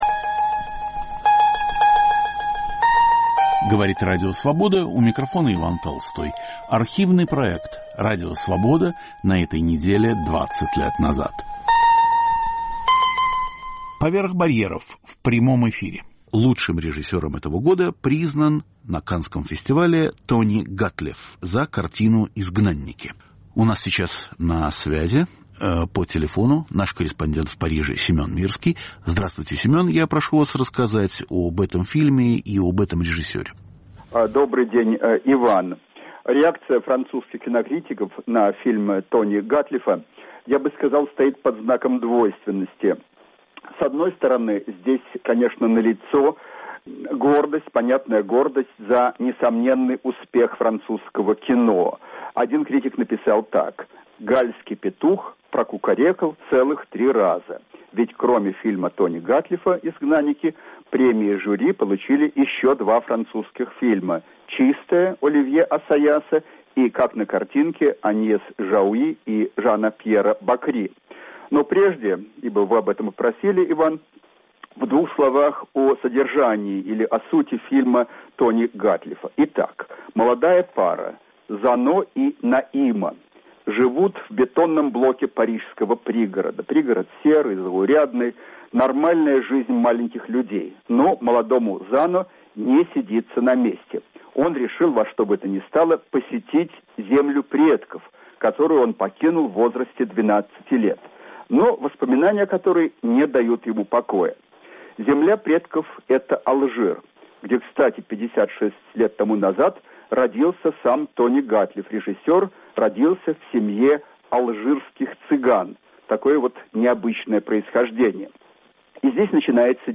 "Поверх барьеров" в прямом эфире. О режиссере Тони Гатлифе и его фильме "Изгнанники"
О лучшем режиссере 2004 года и его фильме в жанре роуд-муви. Итоги Каннского фестиваля. В программе участвуют кинокритики и корреспонденты из Парижа, Нью-Йорка и Москвы.